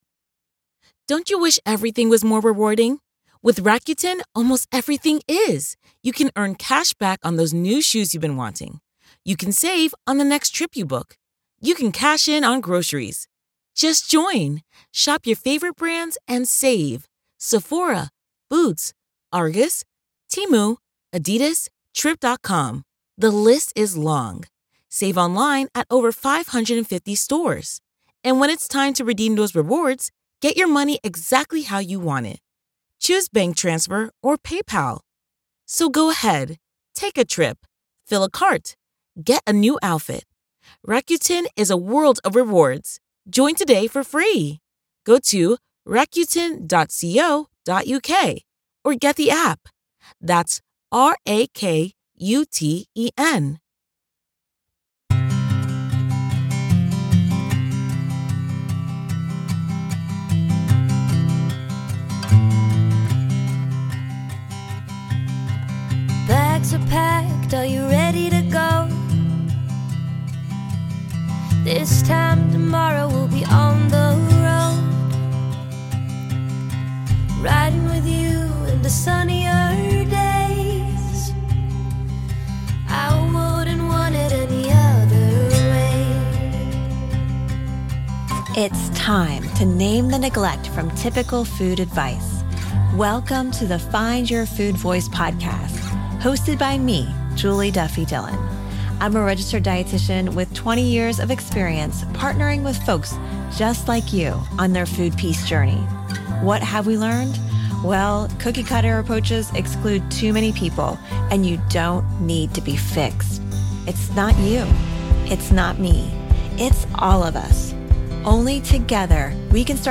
Does diet culture ever feel like a constant noise you can't turn off? This guided imagery will help you find your food voice.